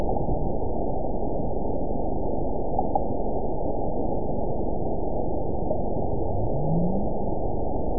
event 912321 date 03/24/22 time 14:24:43 GMT (3 years, 1 month ago) score 9.40 location TSS-AB05 detected by nrw target species NRW annotations +NRW Spectrogram: Frequency (kHz) vs. Time (s) audio not available .wav